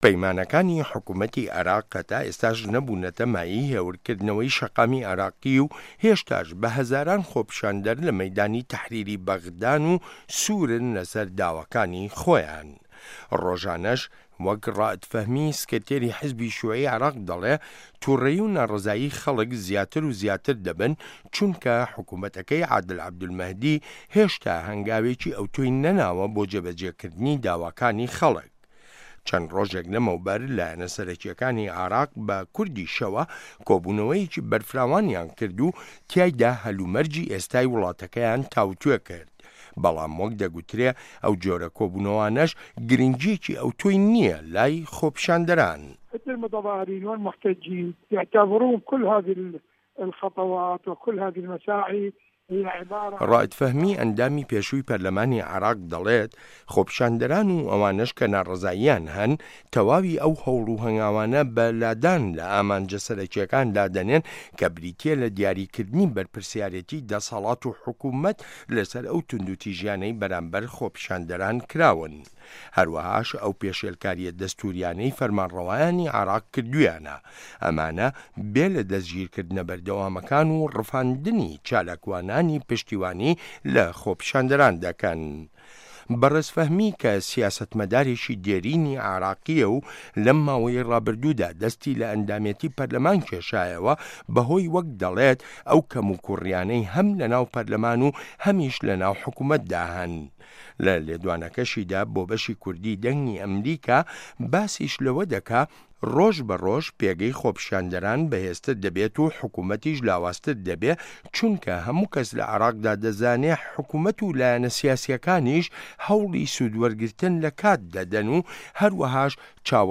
ڕاپۆرت لەسەر بنچینەی لێدوانەکانی ڕائید فەهمی